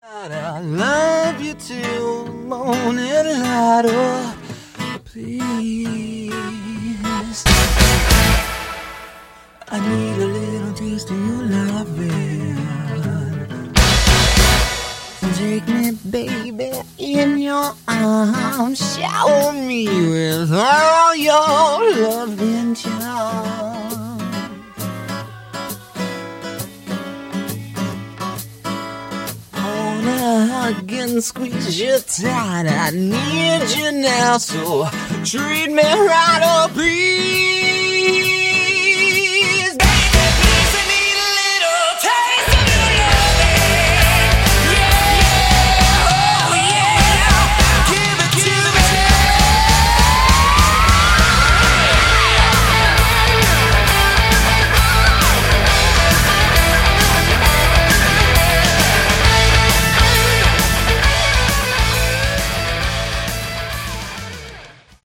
Category: Hard Rock
lead vocals
keyboards, vocals
bass, vocals
drums